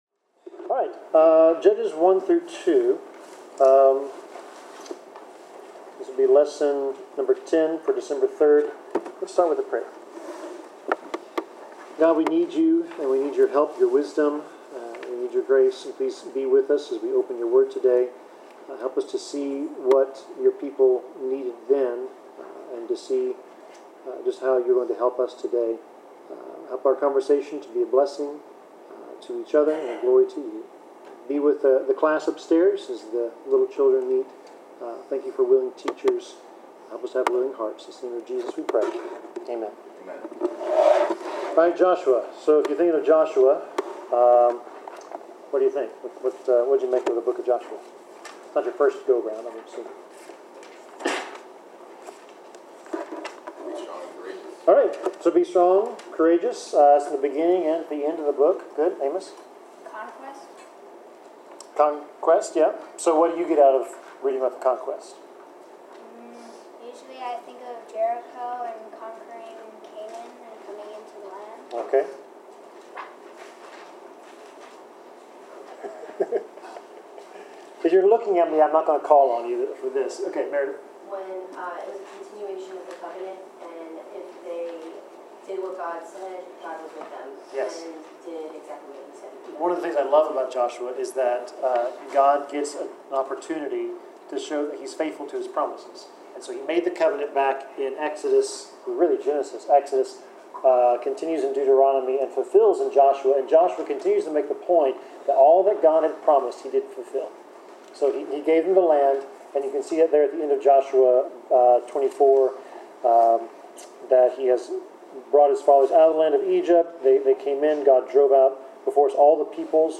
Bible class: Introduction to the Book of Judges
Passage: Judges 1-2 Service Type: Bible Class